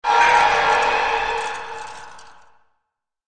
playerdies_1.ogg